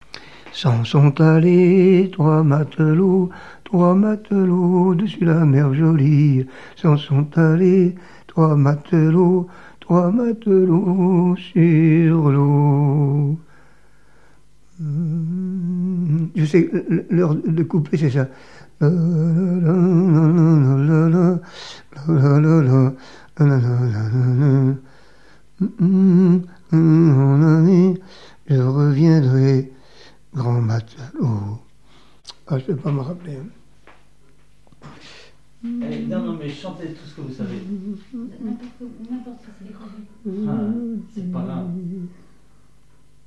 témoignages et chansons maritimes
Pièce musicale inédite